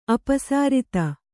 ♪ apasřta